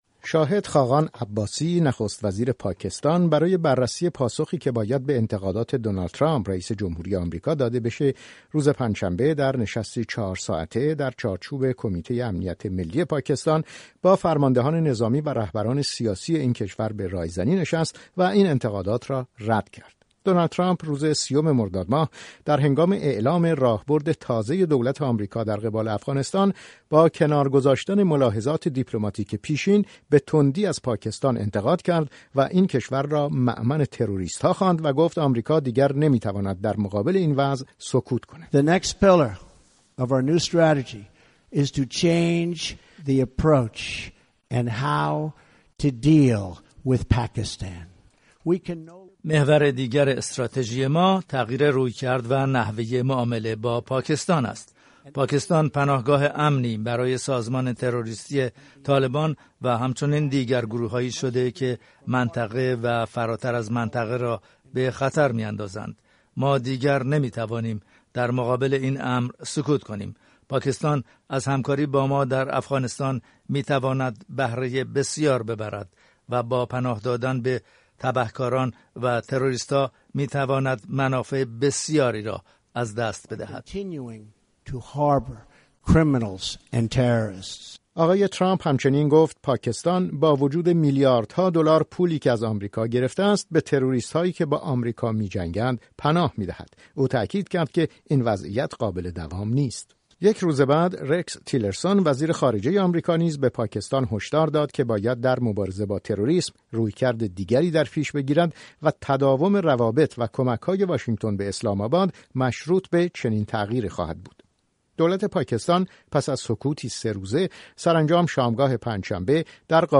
گزارش رادیویی درباره واکنش پاکستان به انتقادات دونالد ترامپ از عملکرد اسلام‌آباد